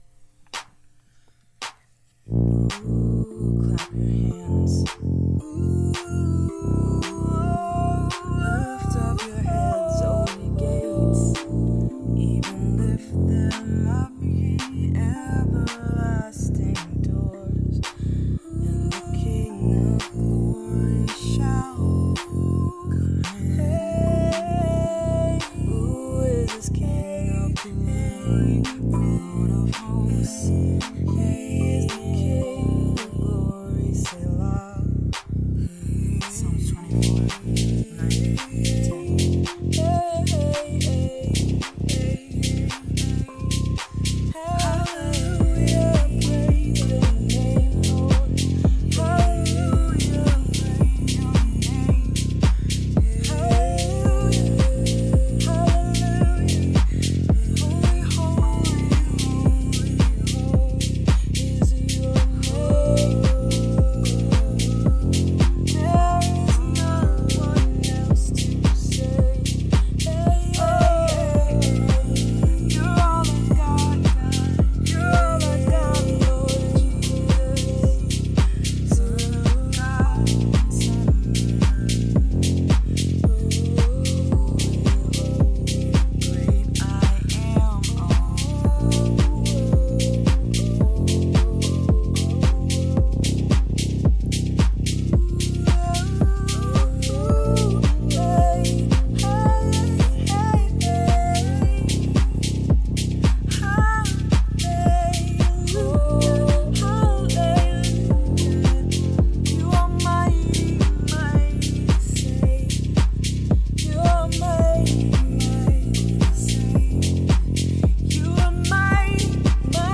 Rav Vast & Beats Sessions Stream SpontaneousThankYouJesusRavVastandBeatsSessions.mp3 Download Related 0 Shares Post Tags: # 11-11-24 # 2024 # drum # improv # improvised worship # music # Nov. # November # Rav vast # Rav vast drum # songs # spiritual songs # spontaneous # spontaneous worship